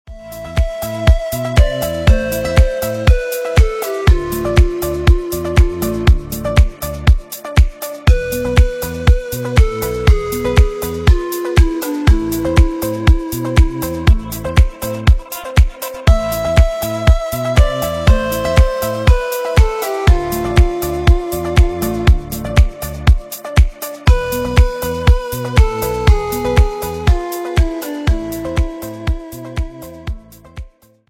зарубежные без слов